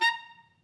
Clarinet
DCClar_stac_A#4_v2_rr1_sum.wav